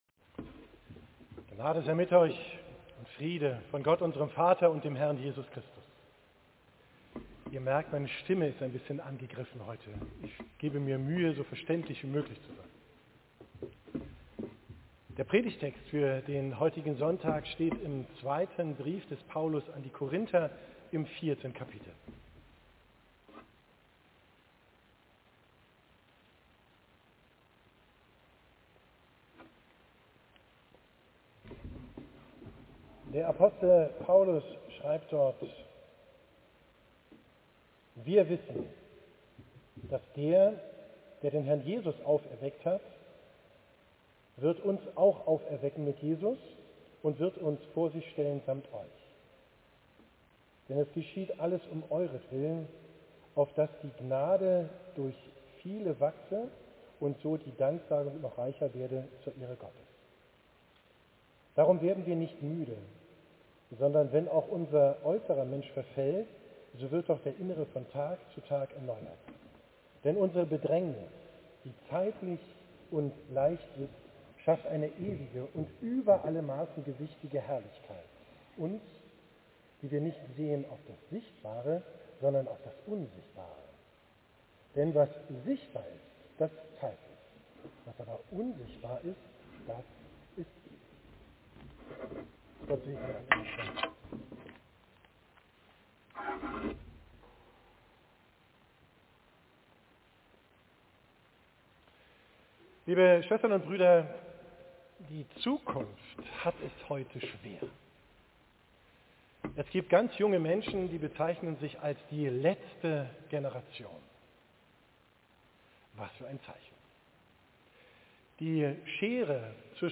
Predigt am Sonntag Jubilate, 21.